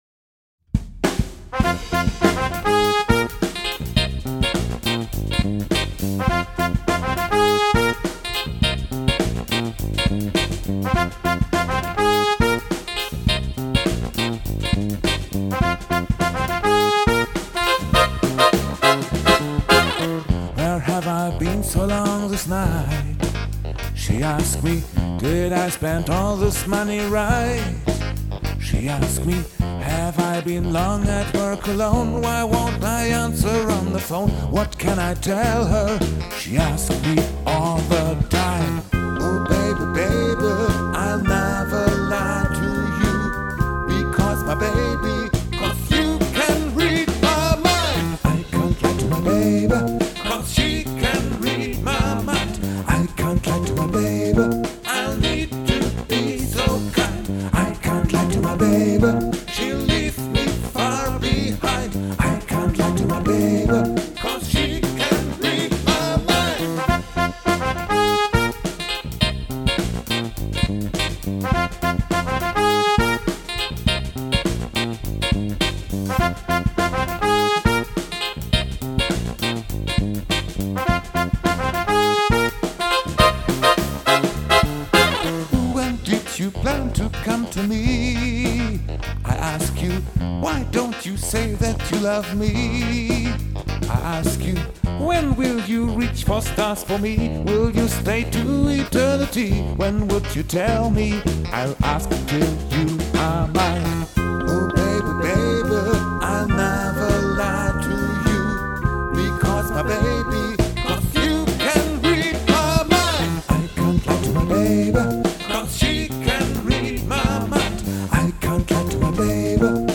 Drums
Bass